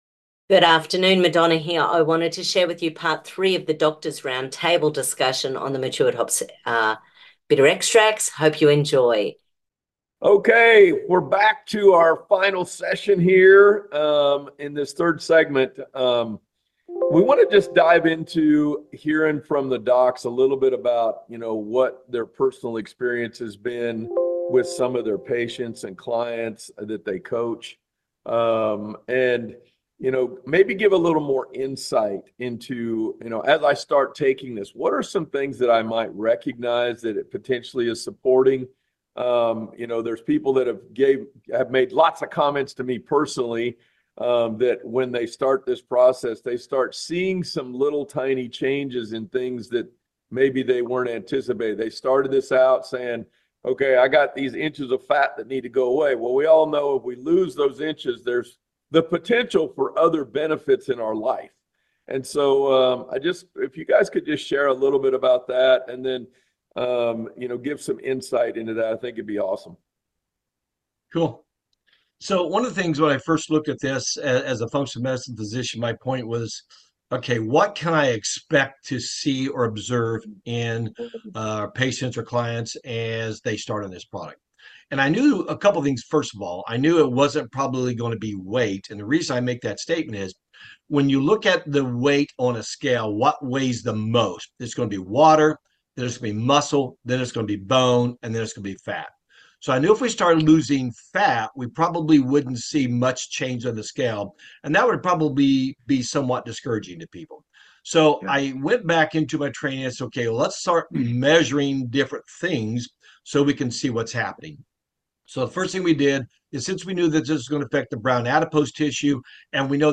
Part 3 Roundtable Discussion on Matured Hops Bitter Extracts! (pHix)... thanks guys!!